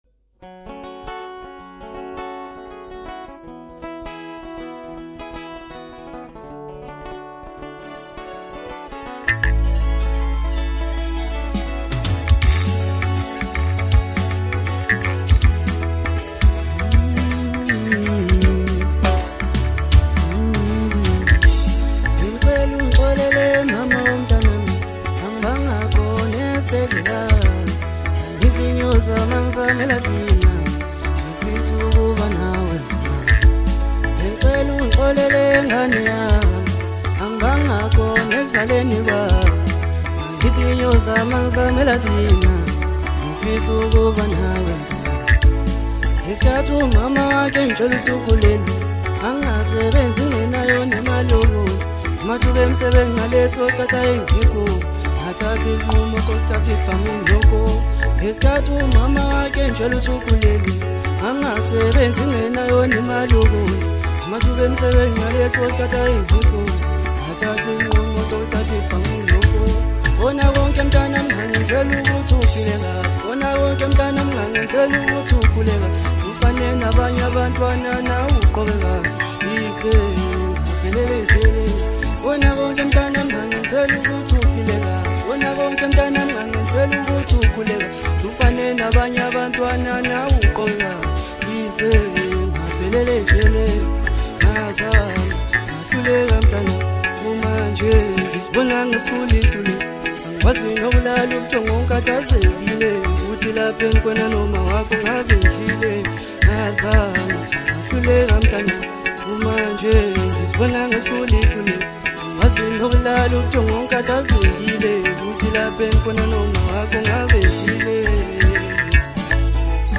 • Genre: Maskandi